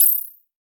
Simple Digital Connection 7.wav